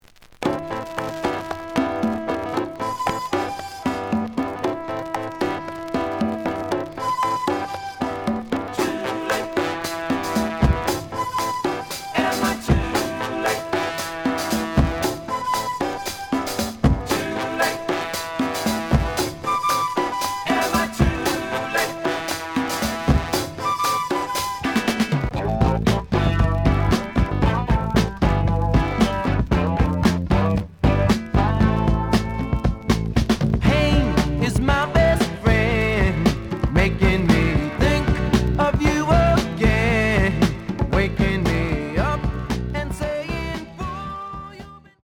The audio sample is recorded from the actual item.
●Format: 7 inch
●Genre: Soul, 70's Soul
Some click noise on beginning of A side.